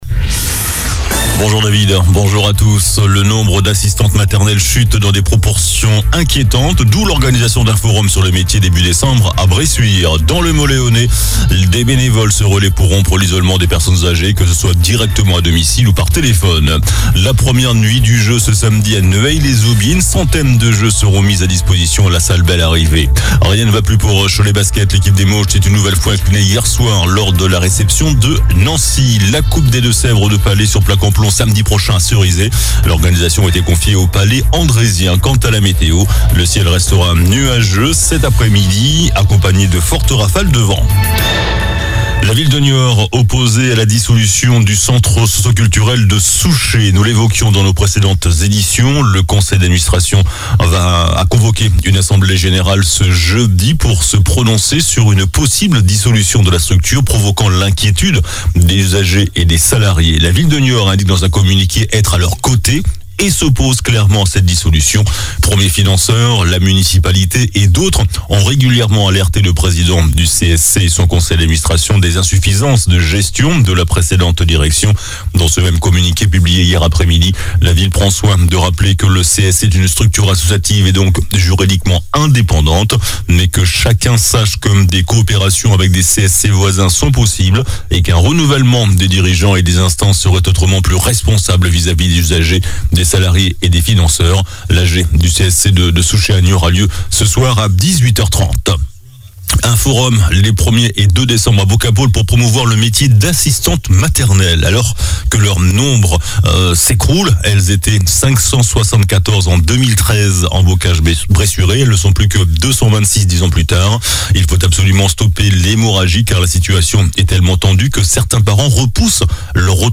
JOURNAL DU JEUDI 16 NOVEMBRE ( MIDI )